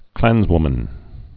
(klănzwmən)